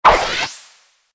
audio: Converted sound effects
SA_powertie_throw.ogg